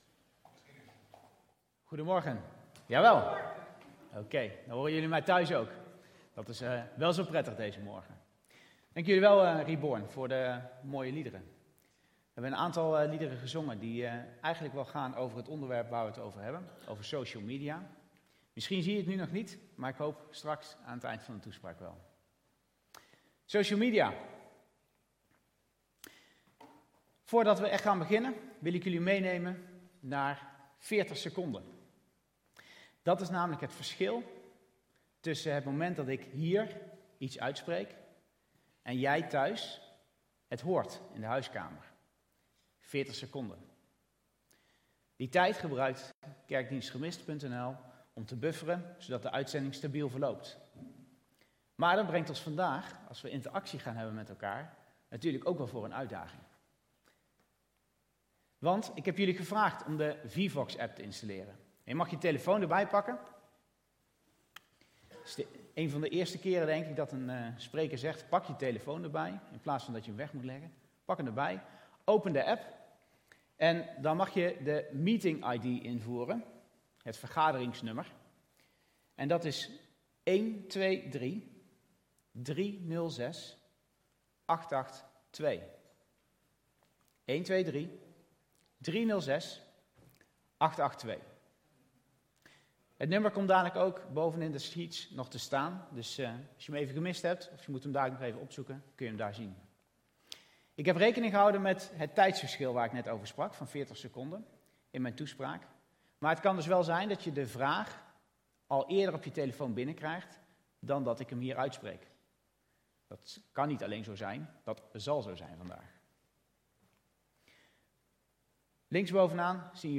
Toespraak 21 maart: Social Media - De Bron Eindhoven